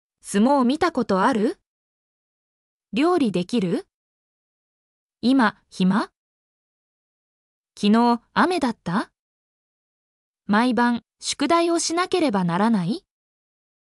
mp3-output-ttsfreedotcom-85_vhTOjYYM.mp3